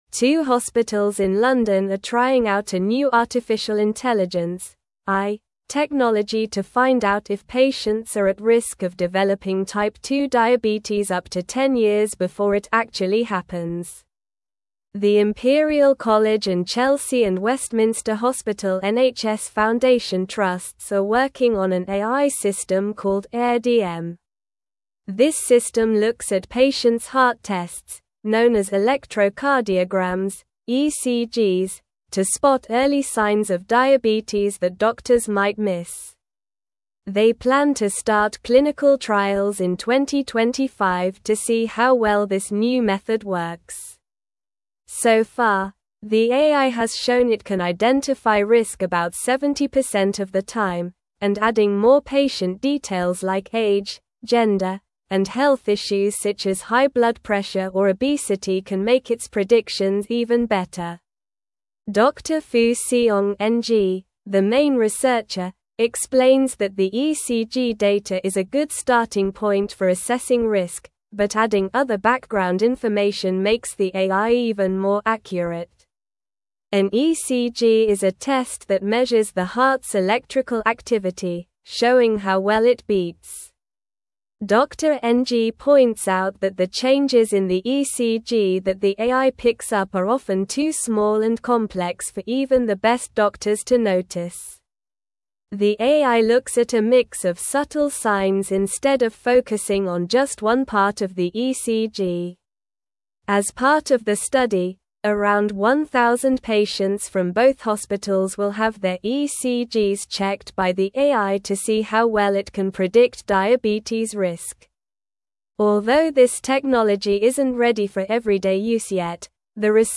Slow
English-Newsroom-Upper-Intermediate-SLOW-Reading-AI-System-Predicts-Type-2-Diabetes-Risk-Early.mp3